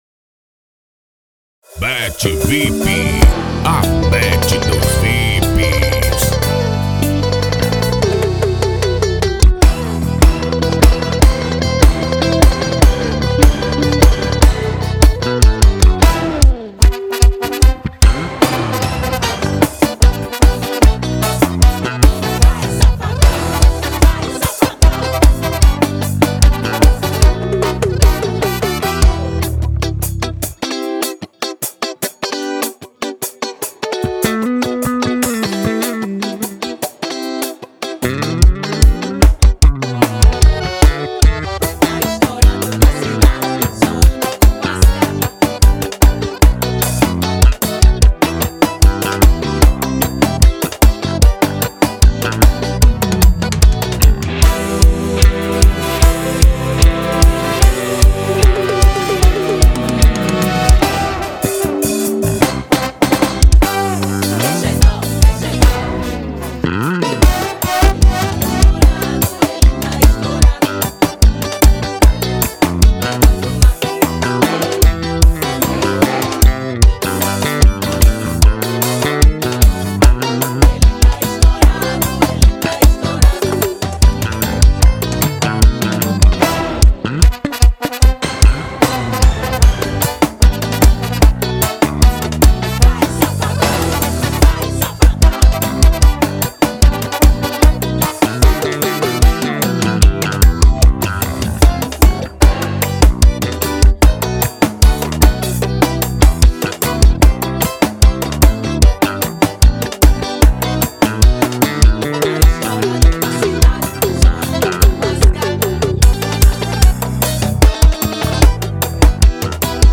2024-02-14 22:40:16 Gênero: Forró Views